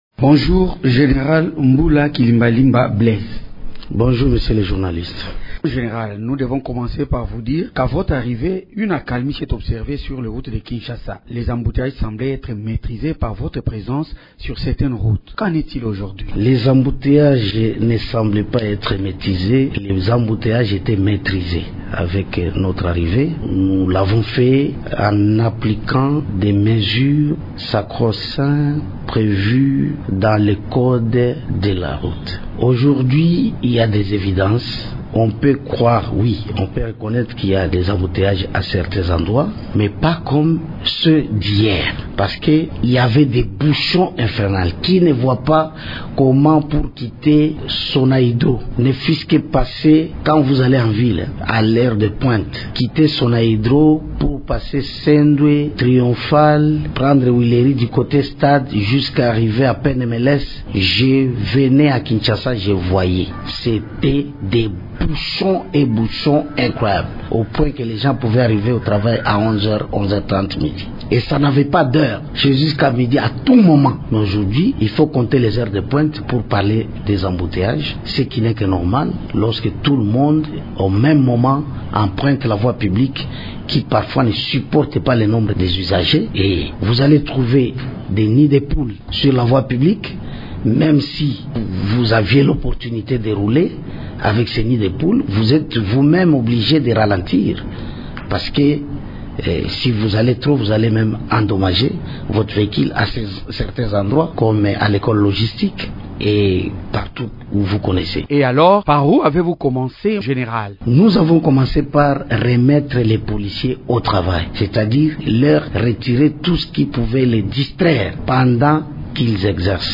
Le Commandant de la PNC dans la ville de Kinshasa s’entretient